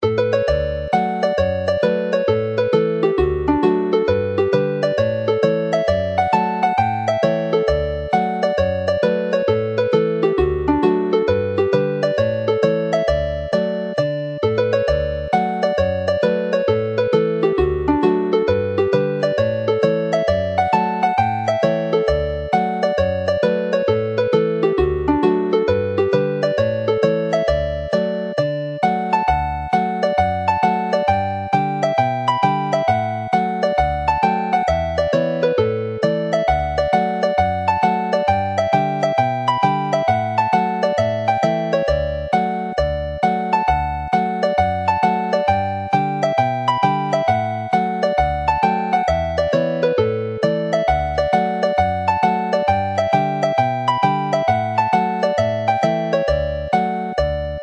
Chwarae'r alaw fel pibddawns
Play the melody as a hornpipe